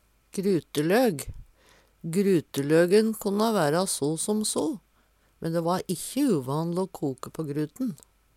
gruteløg - Numedalsmål (en-US)